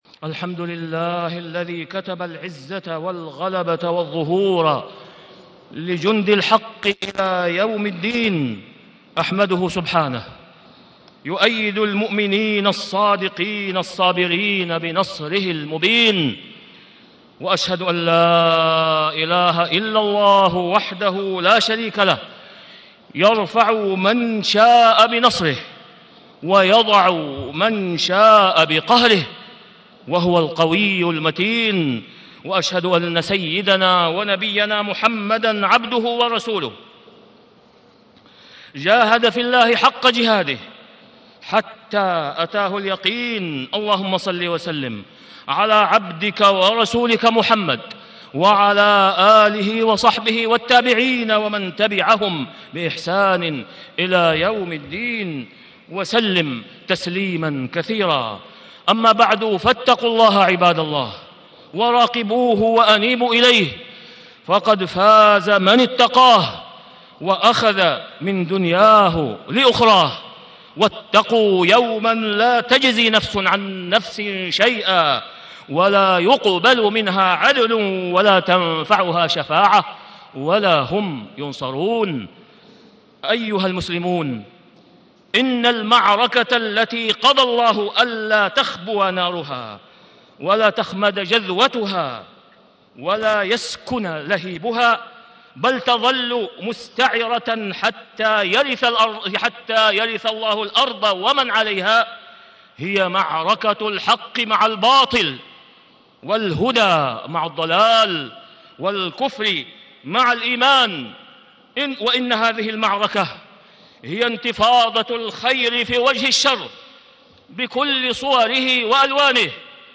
تاريخ النشر ١٩ رجب ١٤٣٦ هـ المكان: المسجد الحرام الشيخ: فضيلة الشيخ د. أسامة بن عبدالله خياط فضيلة الشيخ د. أسامة بن عبدالله خياط الصراع بين الحق والباطل